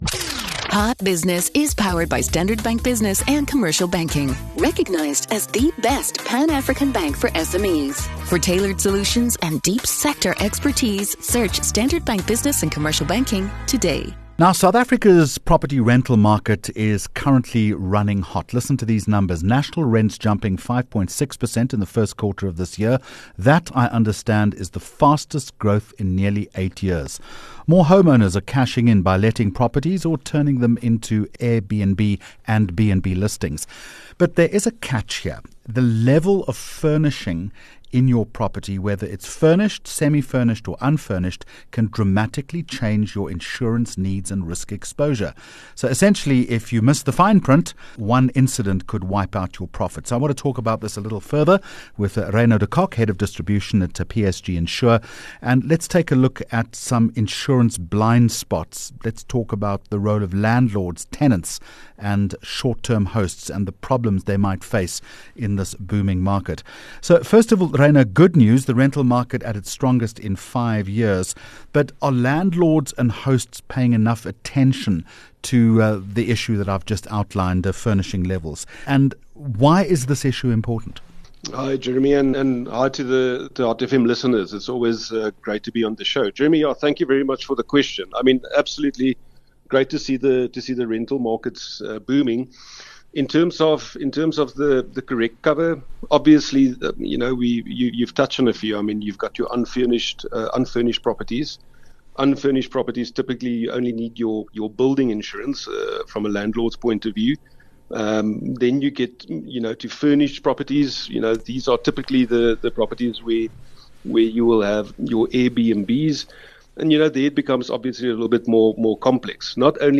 12 Aug Hot Business interview